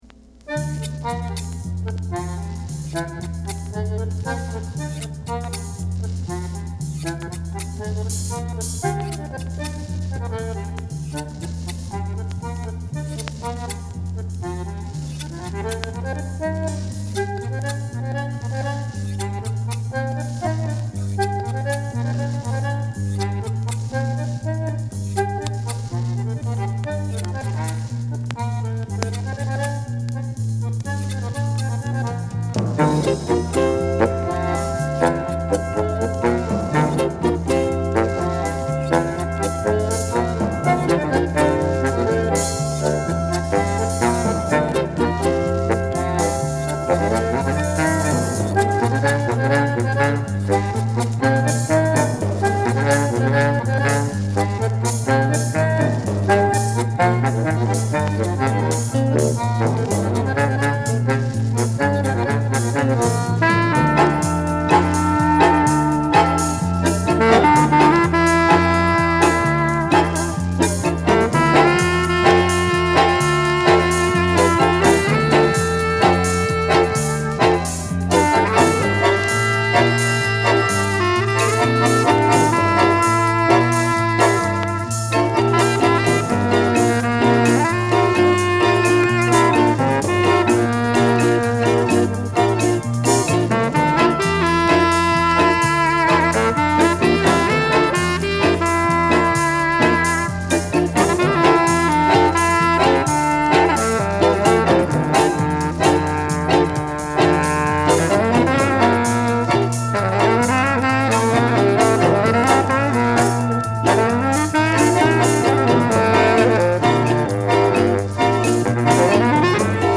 Genre: Tango / Jazz Jacket
バリトン・サックスとバンドネオンという異質な組み合わせが